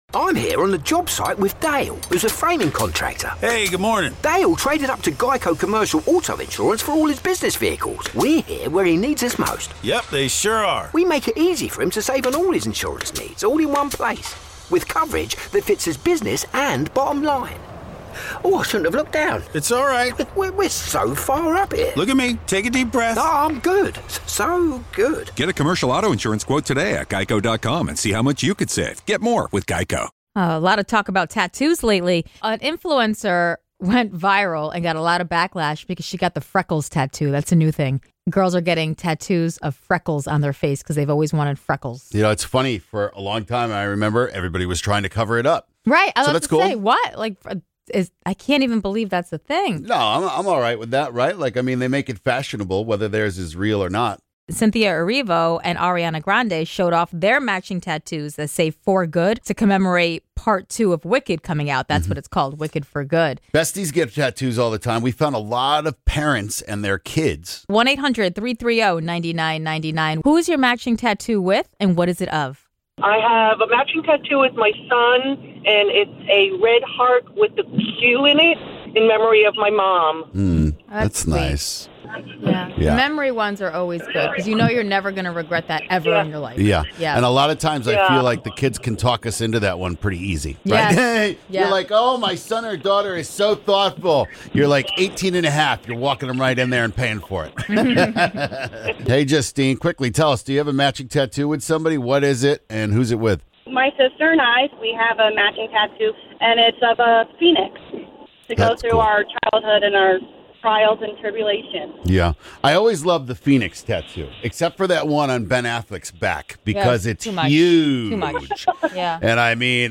The Office Squad and callers share what their first world complaint is! Someone from Gen Z took 10 days off work, and the boss approved it!